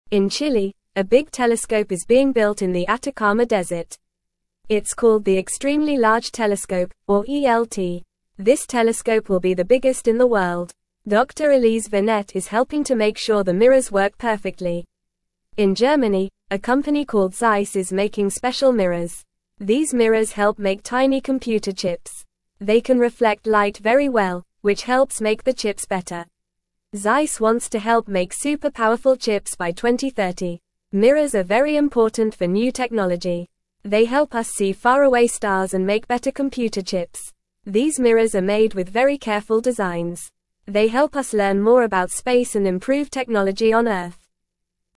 Fast
English-Newsroom-Lower-Intermediate-FAST-Reading-Building-a-Big-Telescope-and-Making-Smooth-Mirrors.mp3